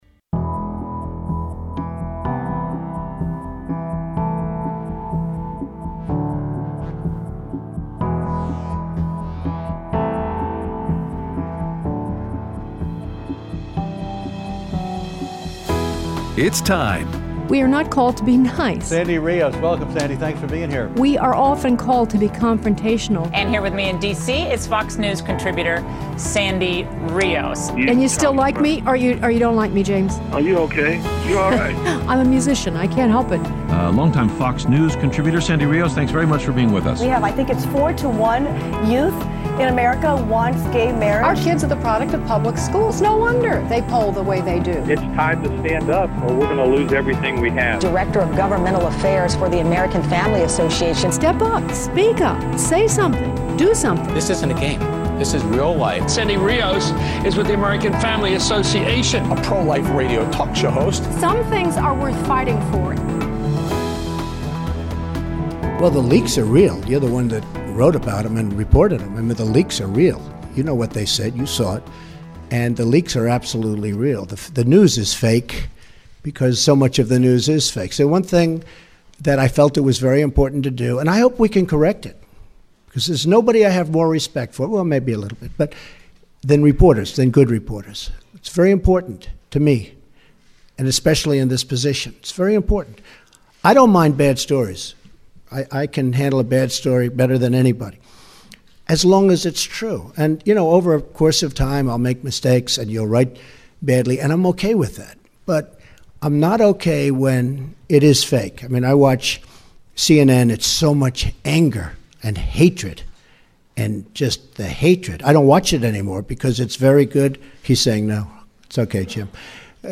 President Trump's Press Conference Yesterday and Your Phone Calls
Aired Friday 2/17/17 on AFR 7:05AM - 8:00AM CST